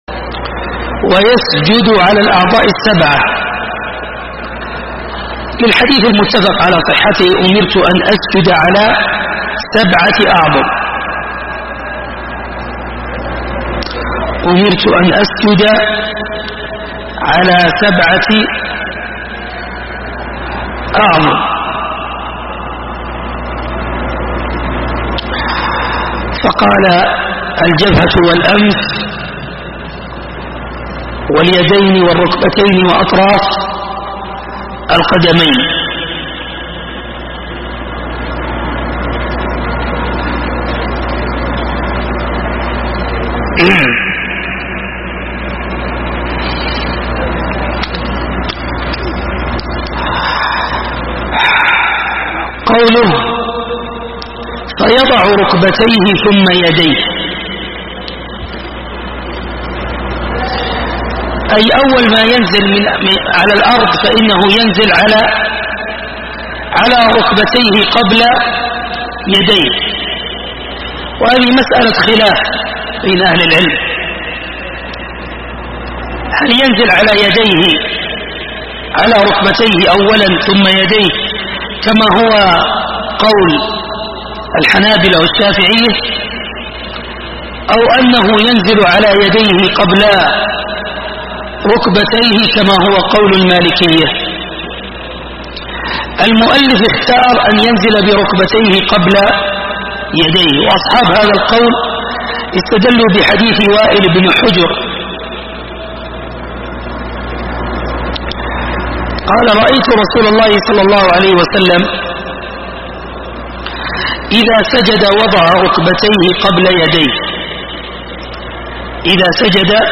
دروس وسلاسل